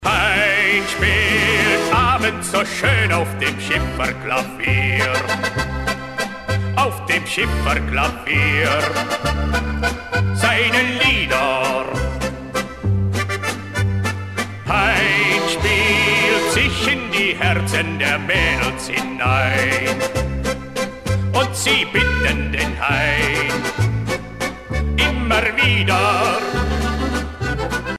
danse : valse musette